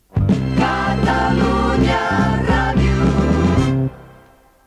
Indicatiu curt